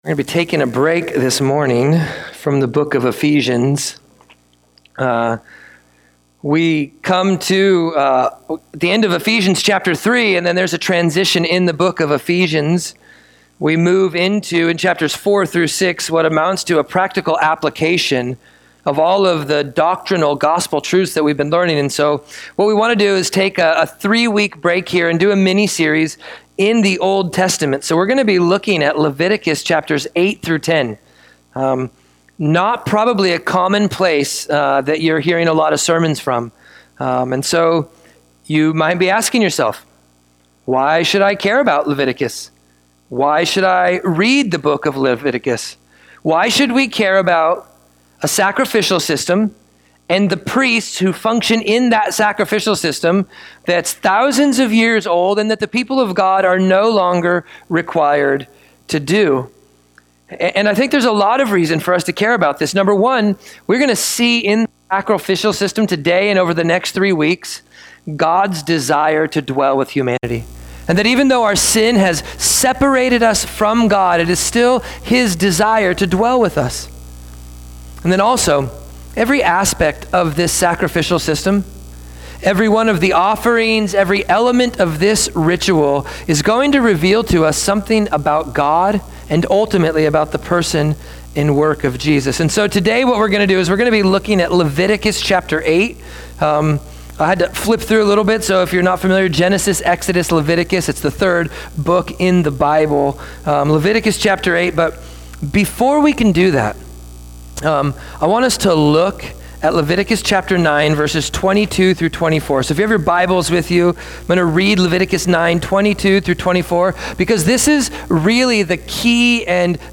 Leviticus-8-Audio-Sermon.mp3